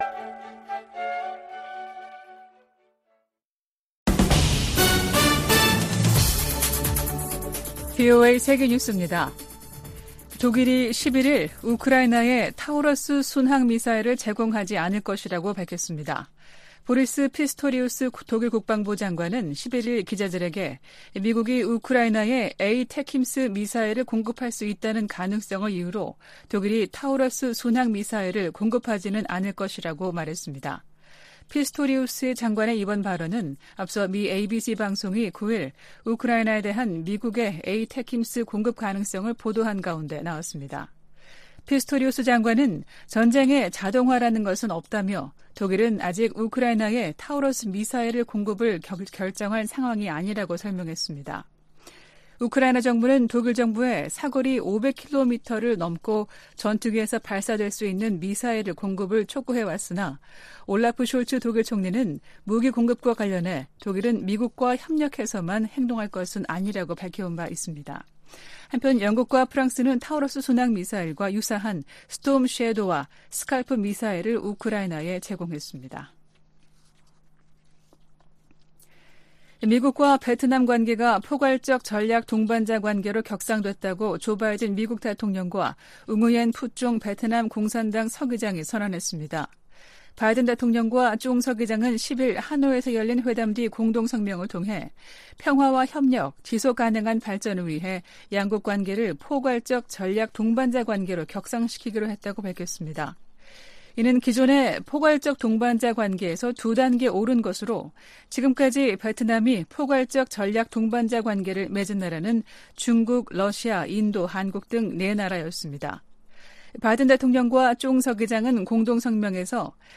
VOA 한국어 아침 뉴스 프로그램 '워싱턴 뉴스 광장' 2023년 9월 12일 방송입니다. 김정은 북한 국무위원장이 탑승한 것으로 보이는 열차가 러시아로 출발한 것으로 한국 언론들이 보도했습니다. 미국은 러시아와 북한간 무기 거래 저지를 위해 다양한 노력을 기울이면서 상황을 계속 주시하고 있다고 백악관 고위 관리가 밝혔습니다. 러시아가 북한·이란과 공개 공개적으로 연대하고 있으며, 중국도 동참하고 있다고 미 상원 공화당 대표가 우려했습니다.